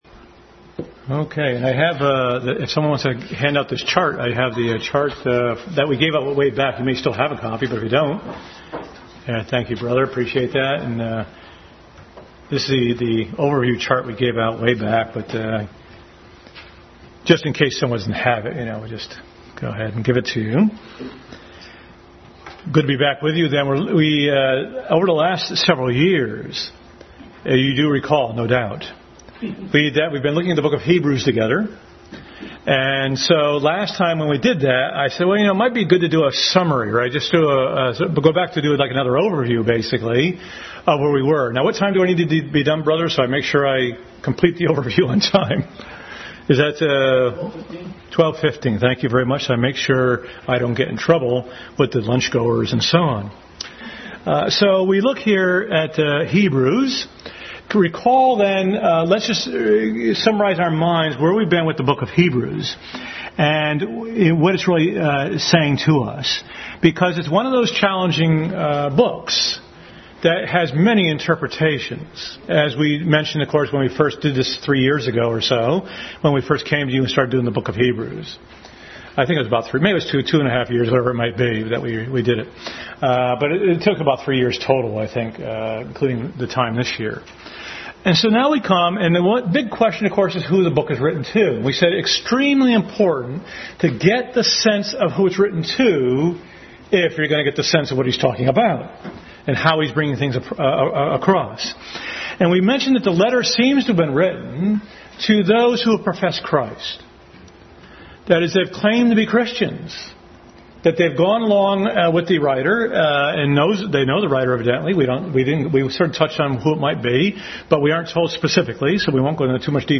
Family Bible Hour Message.
Exodus 6:6-13 Service Type: Family Bible Hour Family Bible Hour Message.